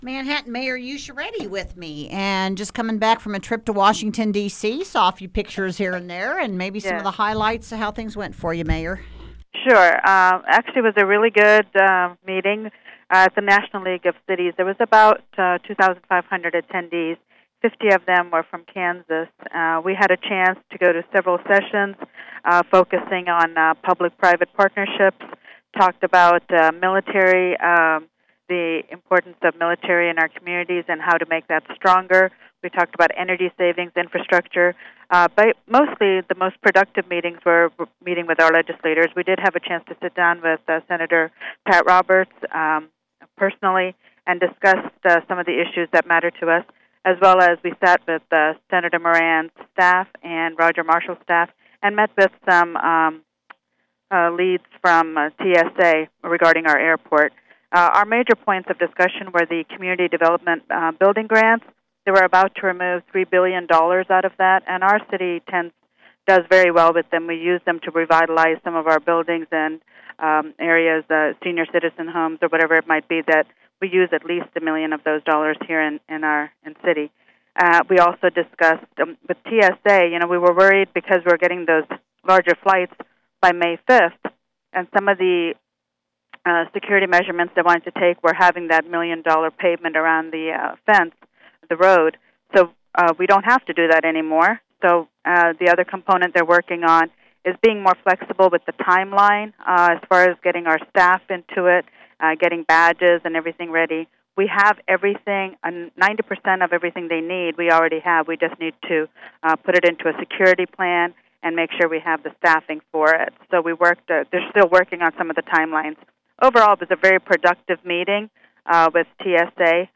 Usha-Reddi-League-of-Cities.wav